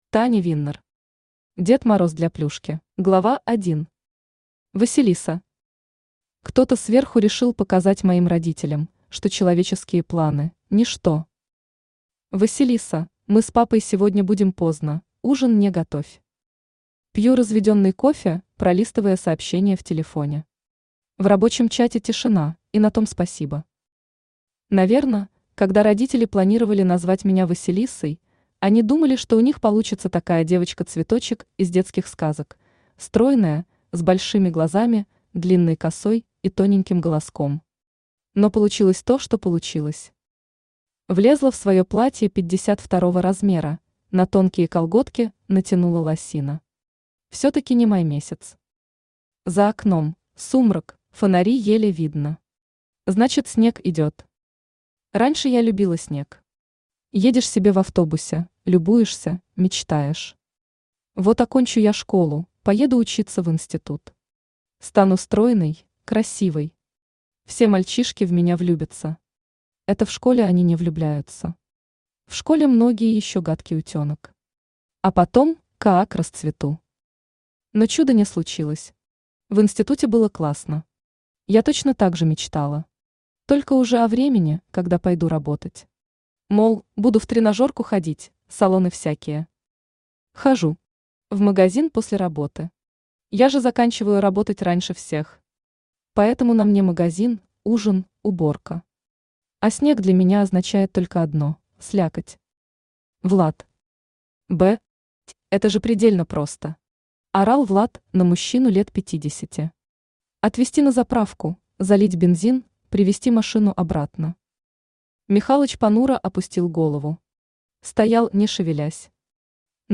Аудиокнига Дед Мороз для Плюшки | Библиотека аудиокниг
Aудиокнига Дед Мороз для Плюшки Автор Таня Виннер Читает аудиокнигу Авточтец ЛитРес.